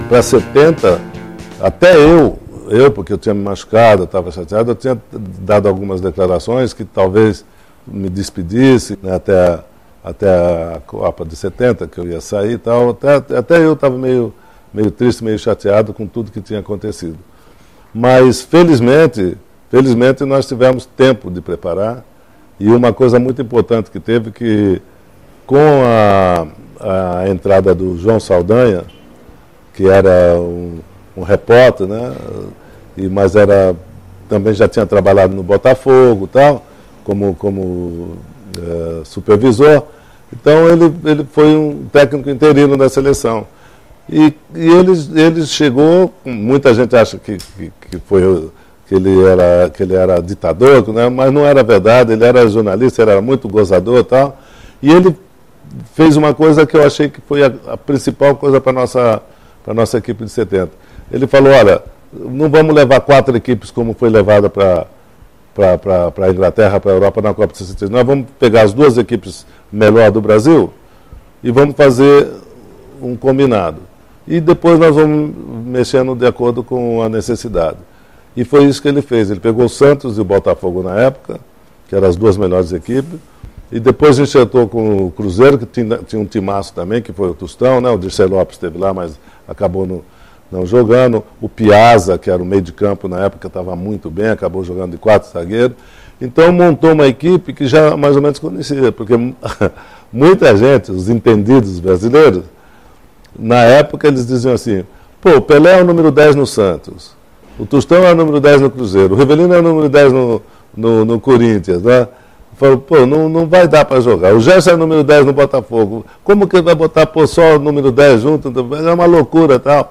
Confira a trajetória de Pelé no futebol com depoimentos e narrações históricas